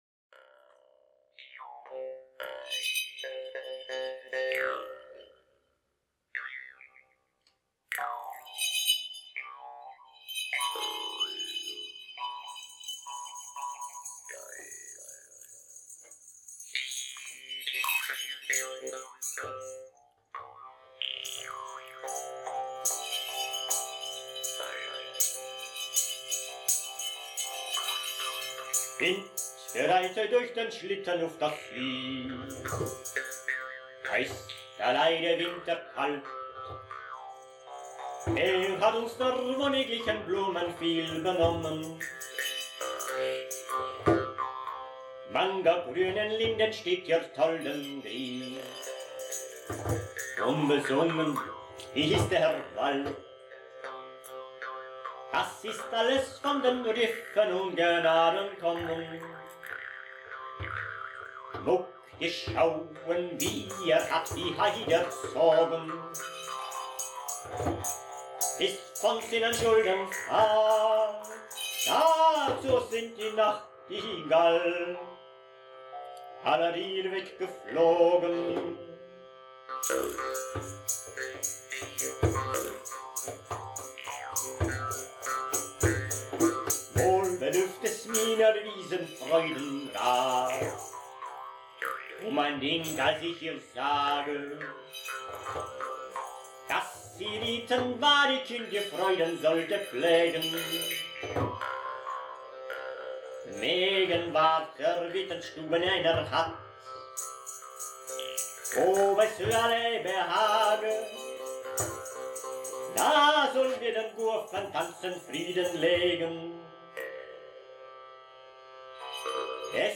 Maultrommeln
Track 1, Typ Gironville: Kint, bereitet iuch der sliten ûf daz îs (Neidhart von Reuental, 13.Jh.)